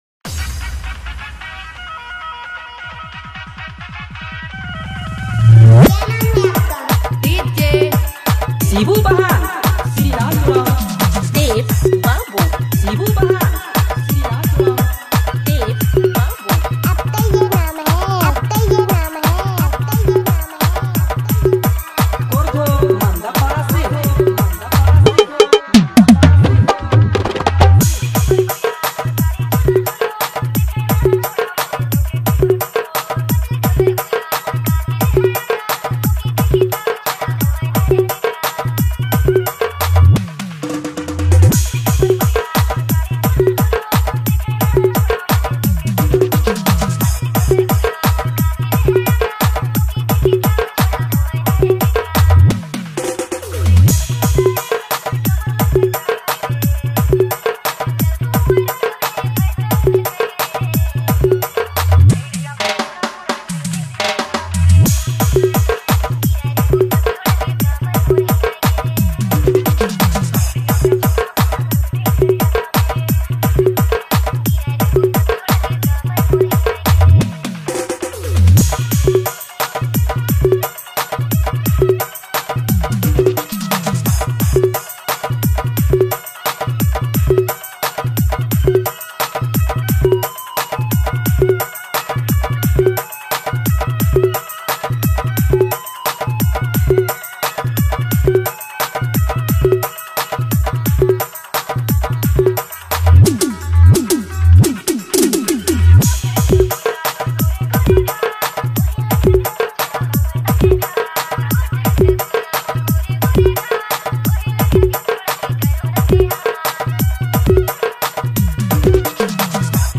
Dj Remixer
New Nagpuri Dj Song 2025